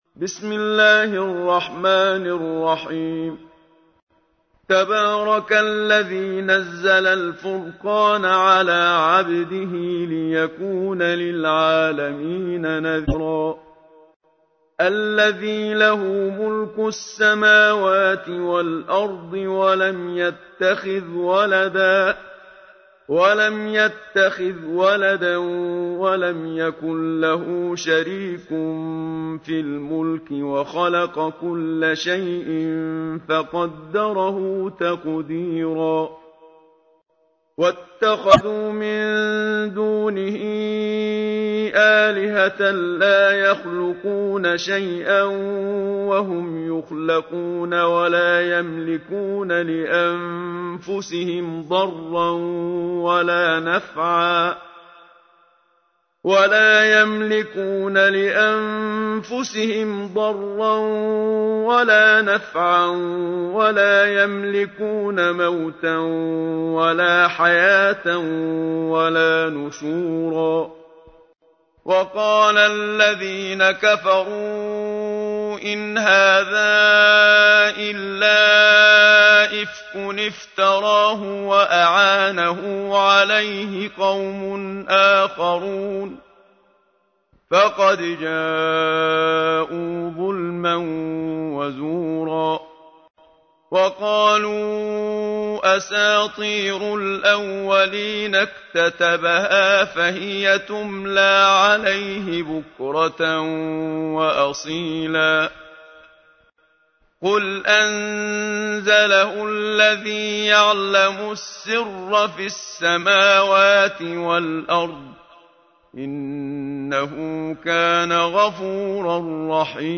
تحميل : 25. سورة الفرقان / القارئ محمد صديق المنشاوي / القرآن الكريم / موقع يا حسين